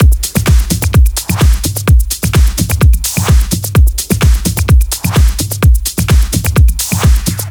VFH1 128BPM Big Tee Kit 1.wav